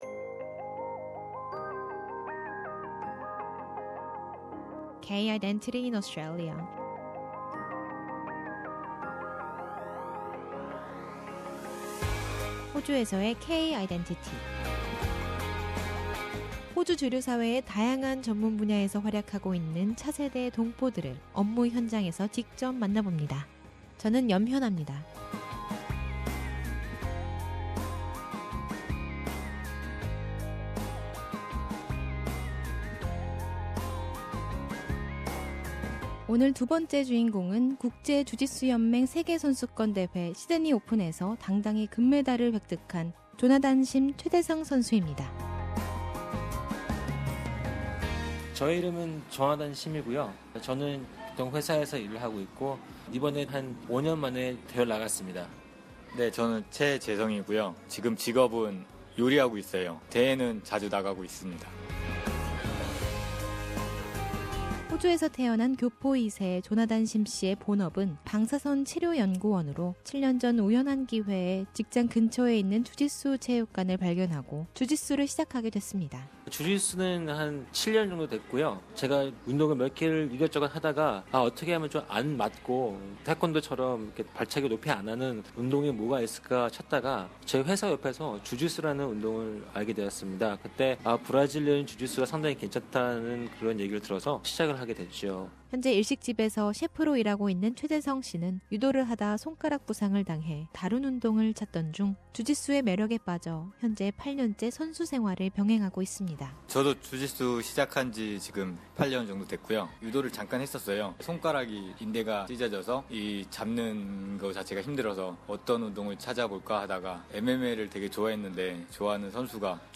K-Identity explores the identity issues through an interview with a variety of second-generation Korean Australian professionals working in various fields of the Australian mainstream.